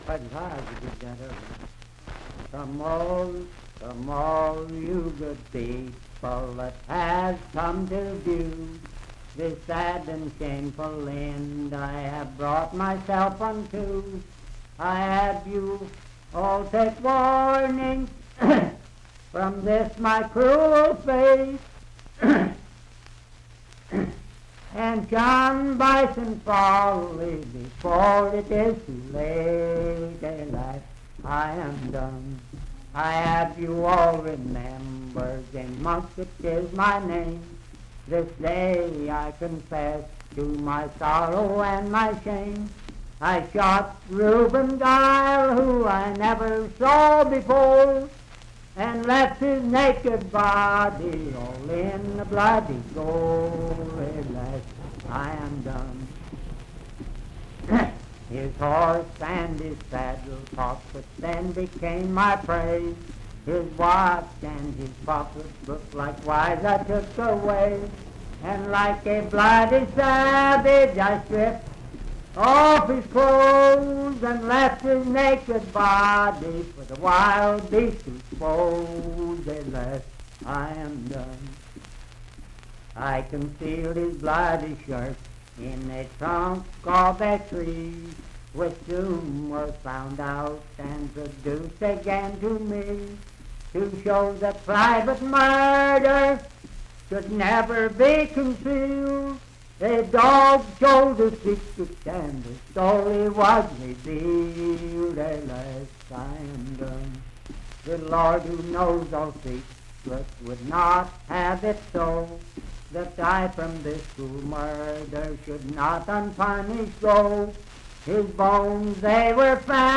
Unaccompanied vocal music
Verse-refrain 5(5w/R).
Voice (sung)